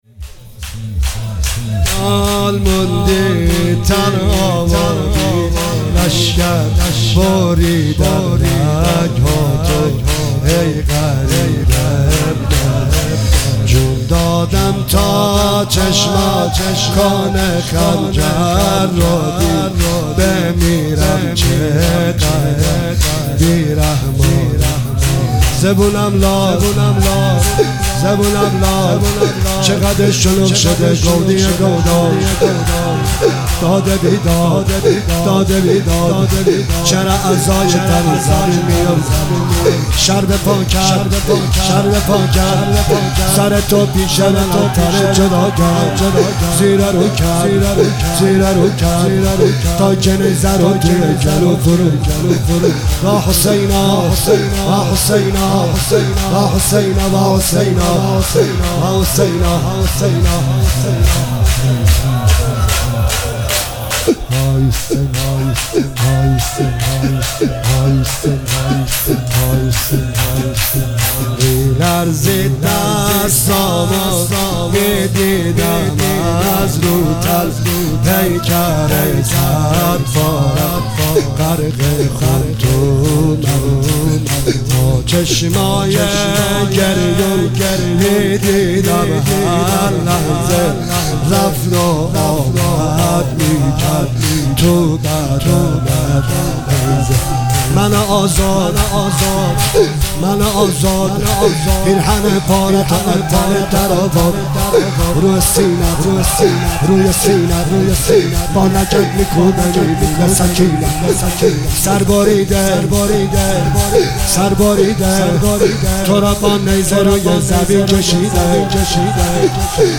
مداحی شور لطمه زنی
روز ششم محرم 1404